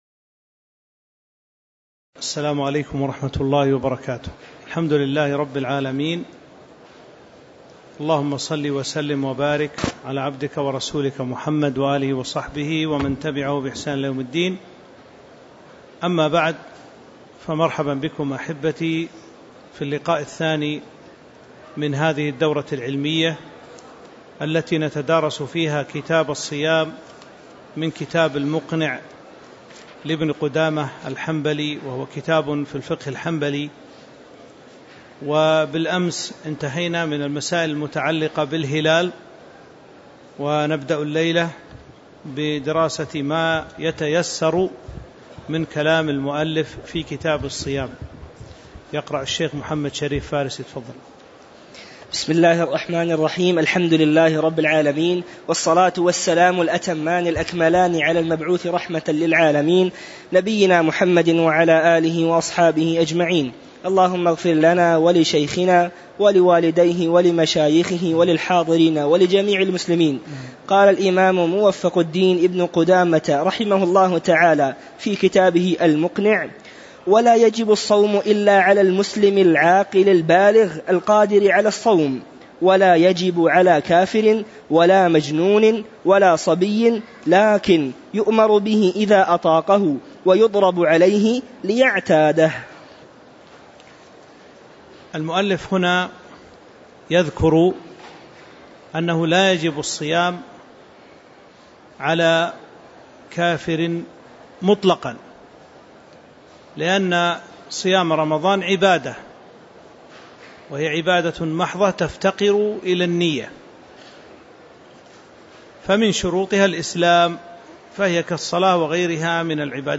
تاريخ النشر ٢٢ شعبان ١٤٤٥ هـ المكان: المسجد النبوي الشيخ